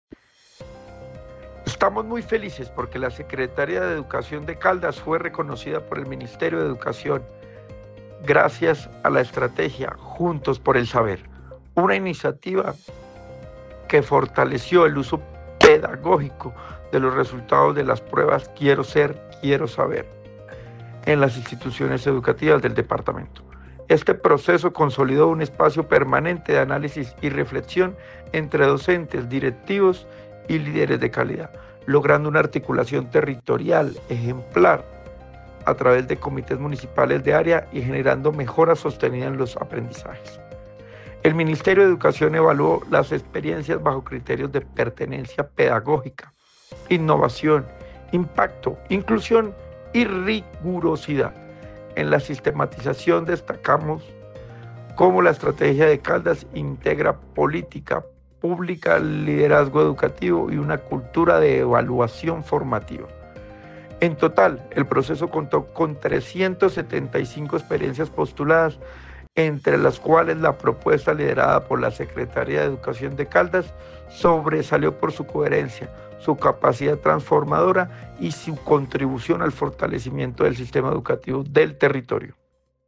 Luis Herney Vargas Barrera, secretario de Educación de Caldas.